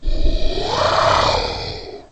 龙的声音 " 龙的咆哮 呼吸 8
描述：为制作史瑞克而制作的龙声。使用Audacity录制并扭曲了扮演龙的女演员的声音。
Tag: 生物 发声 怪物